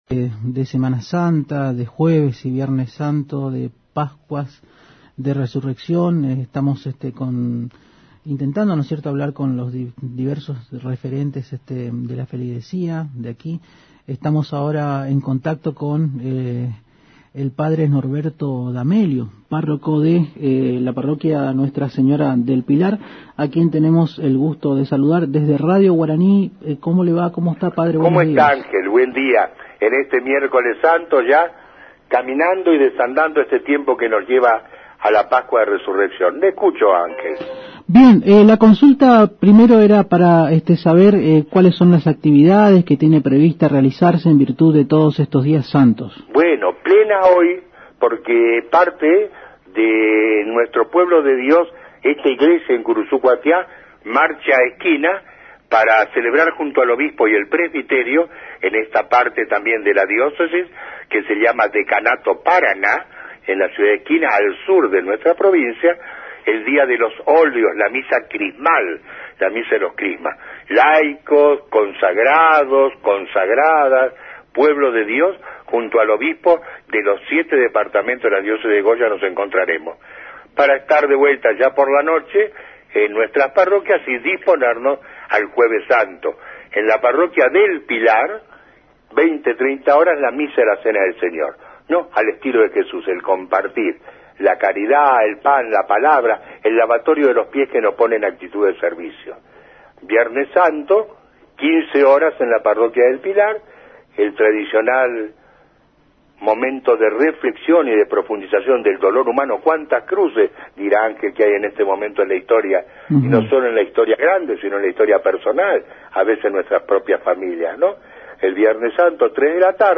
comentó en Agenda 970 a través de la AM 970 Radio Guarani detalles sobre las diversas actividades que se encuentran realizando por la semana santa en Curuzú Cuatiá.